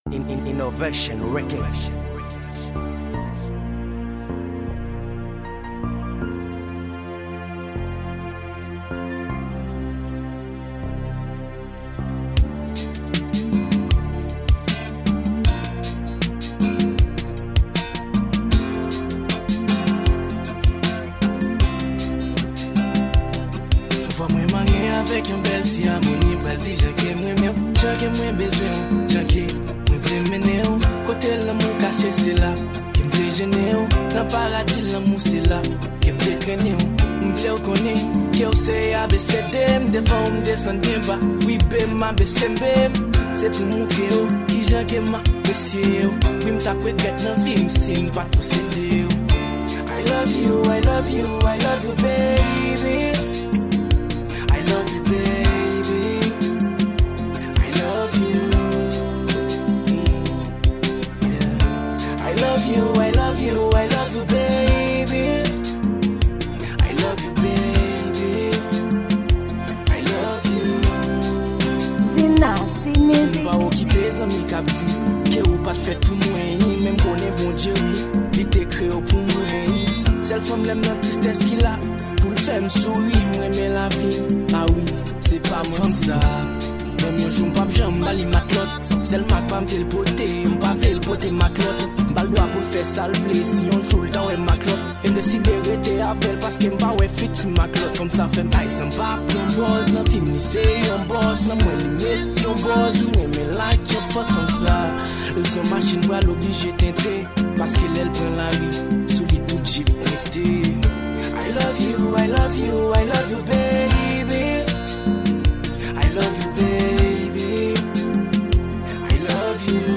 Genre: R&B